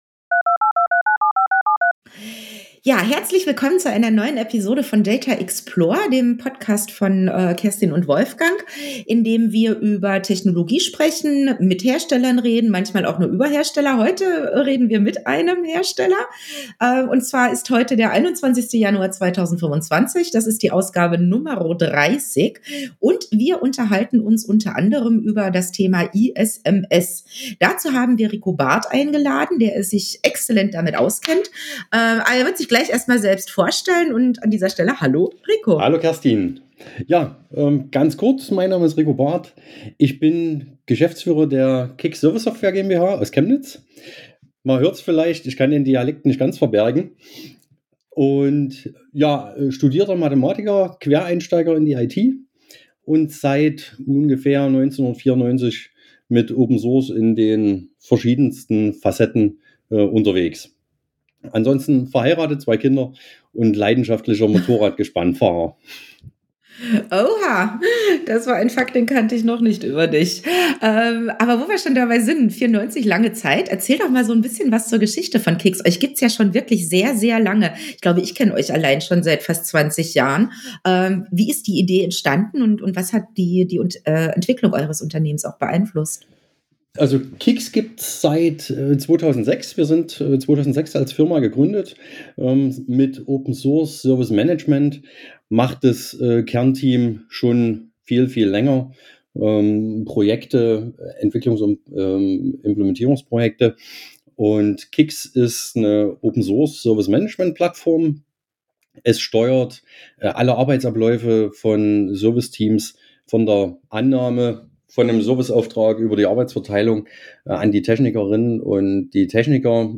Dieses Interview stand schon länger auf unserer Wunschliste.